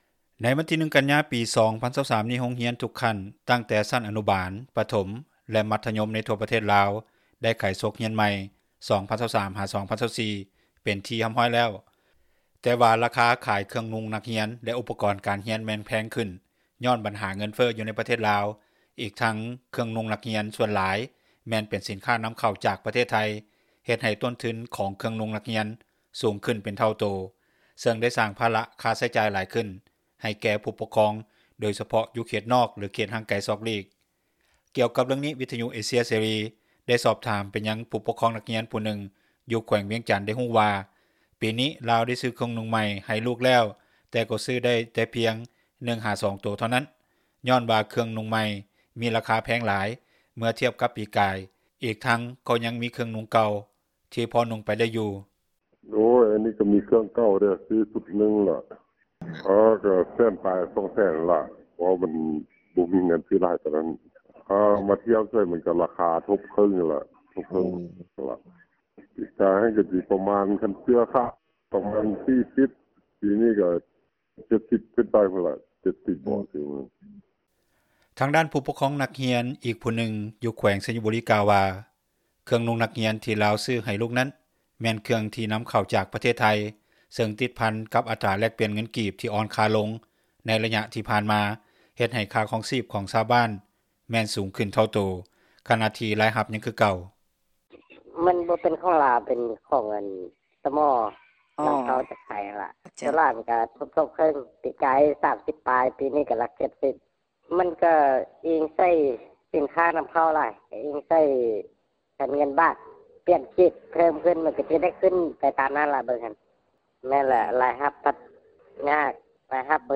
ກ່ຽວກັບເຣື່ອງນີ້, ວິທຍຸເອເຊັຽ ເສຣີ ໄດ້ສອບຖາມໄປຍັງຜູ້ປົກຄອງ ນັກຮຽນຜູ້ນຶ່ງ ຢູ່ແຂວງວຽງຈັນ ໄດ້ຮູ້ວ່າ ປີນີ້ລາວໄດ້ຊື້ເຄື່ອງນຸ່ງໃໝ່ ໃຫ້ລູກແລ້ວແຕ່ກໍຊື້ໄດ້ແຕ່ພຽງ 1-2 ໂຕທໍ່ນັ້ນຍ້ອນວ່າ ເຄື່ອງນຸ່ງໃໝ່ມີລາຄາແພງຫຼາຍ ເມື່ອທຽບກັບປີກາຍອີກທັງ ຍັງມີເຄື່ອງນຸ່ງເກົ່າທີ່ພໍນຸ່ງໄປໄດ້ຢູ່.